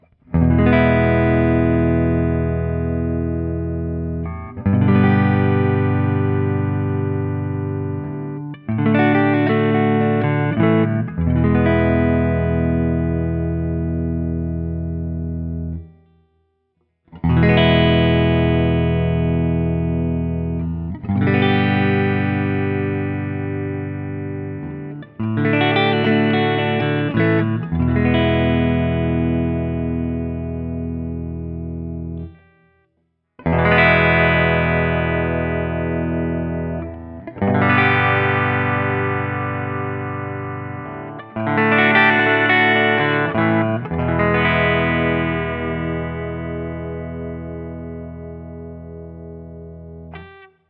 ODS100 Clean
Open Chords #1
What I found instead was a guitar that begged for some gain and played with an articulation and power that caught me off guard.
As usual, for these recordings I used my normal Axe-FX II XL+ setup through the QSC K12 speaker recorded direct into my Macbook Pro using Audacity.
For each recording I cycle through the neck pickup, both pickups, and finally the bridge pickup.